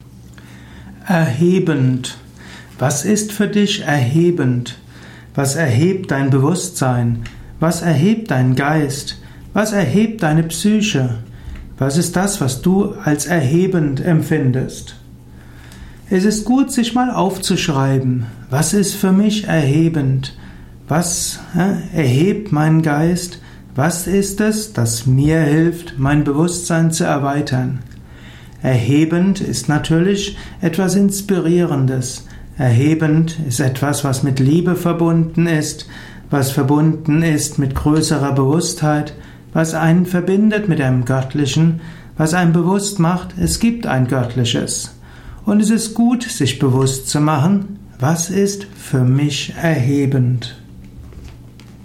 Erhebend Audio Vortrag
Hier findest du die Tonspur des oberen Videos, also einen Audio Vortrag über Erhebend: